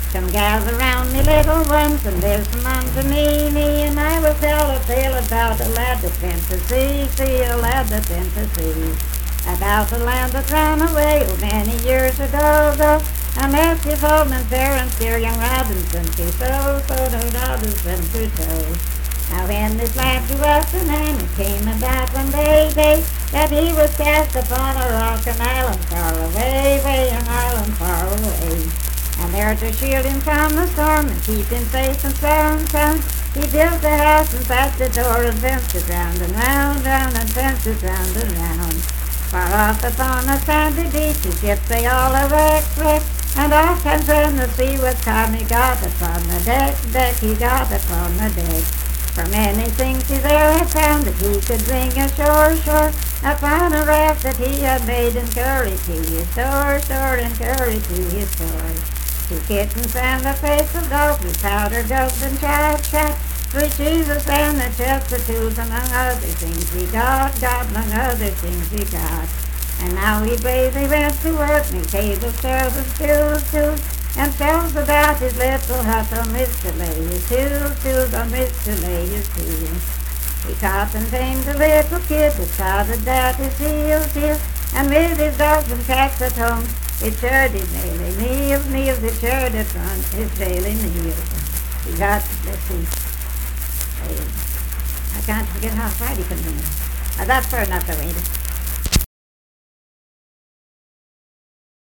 Unaccompanied vocal music performance
Voice (sung)
Jackson County (W. Va.)